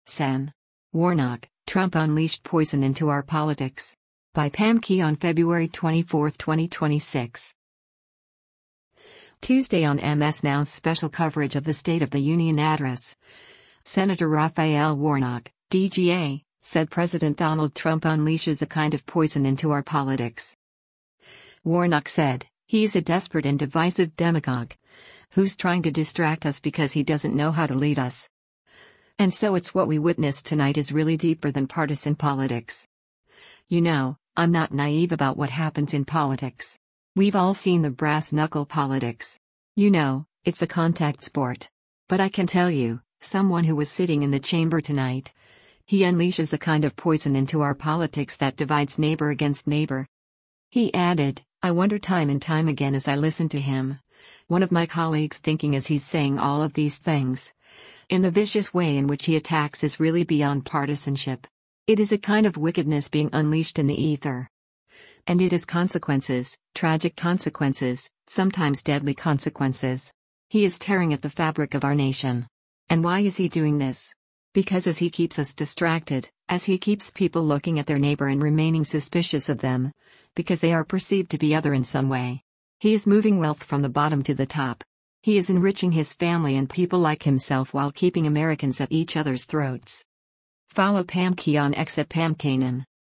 Tuesday on MS NOW’s special coverage of the State of the Union address, Sen Raphael Warnock (D-GA) said President Donald Trump “unleashes a kind of poison into our politics.”